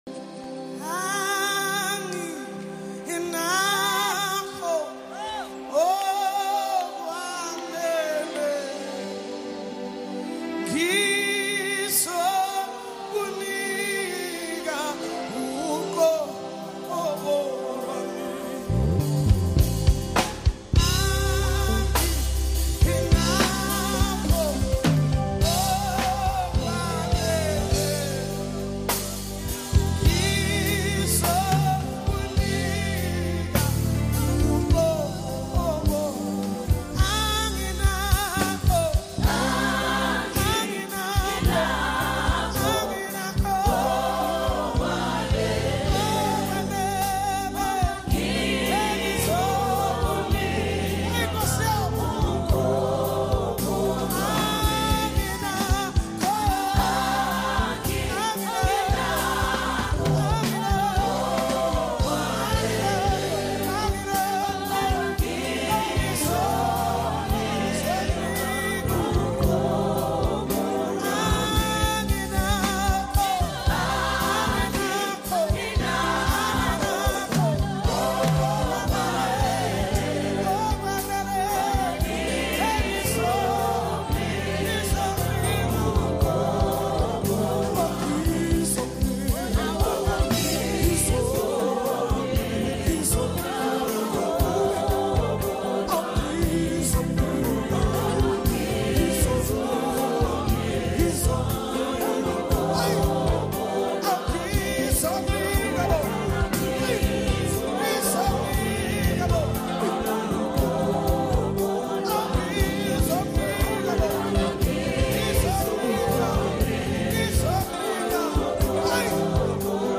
A timeless gospel classic that inspires deep worship
Soulful vocals
📅 Category: South African Classic Worship Song